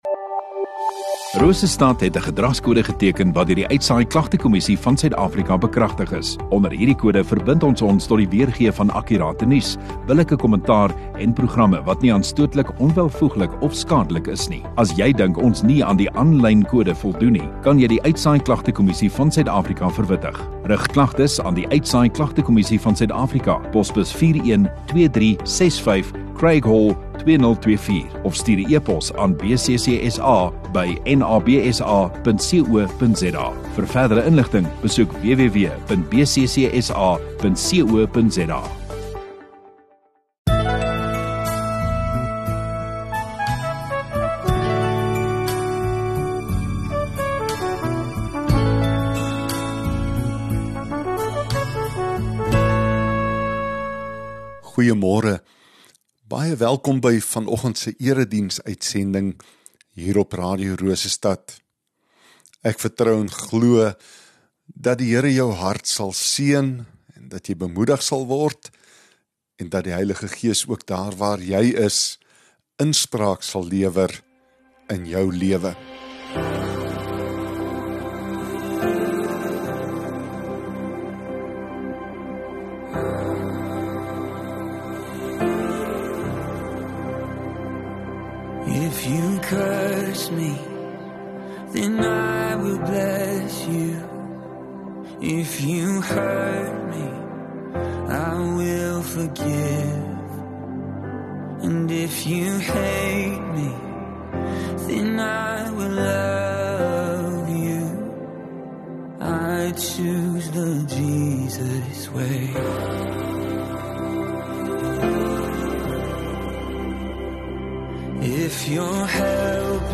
27 Apr Sondagoggend Erediens